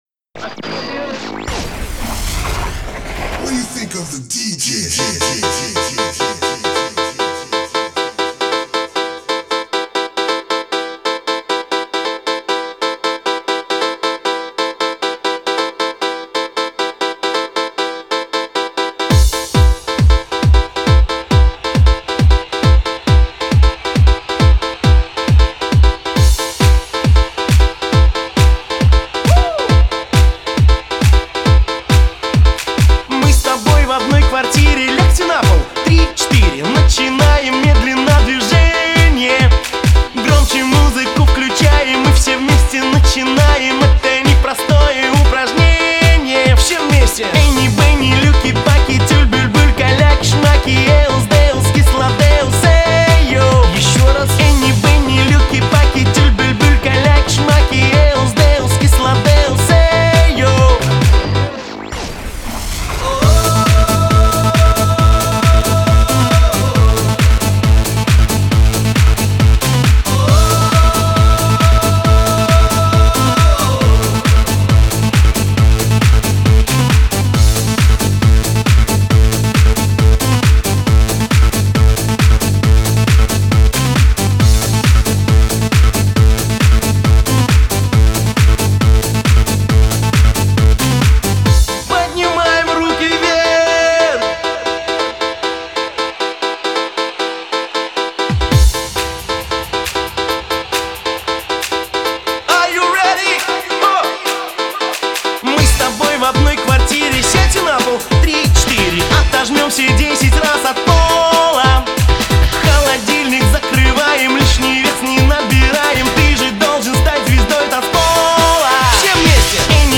Трек размещён в разделе Русские песни / Поп.